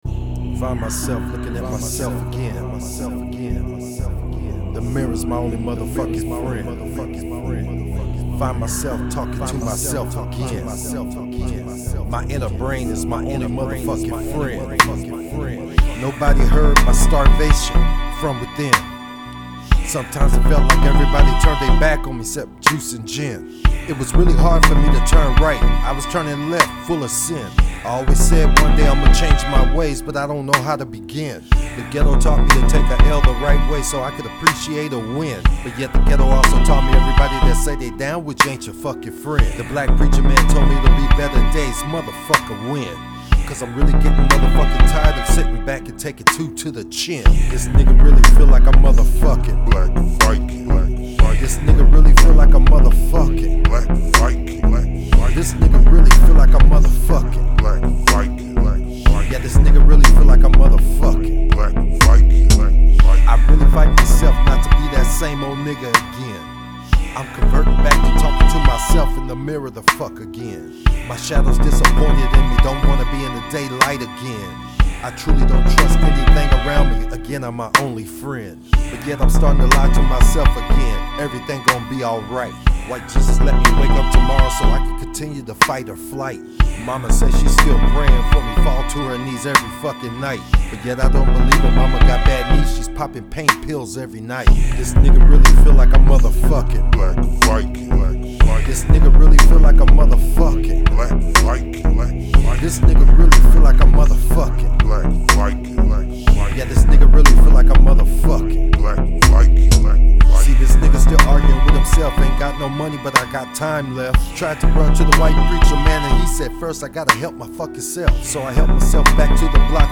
DARK RAP RAP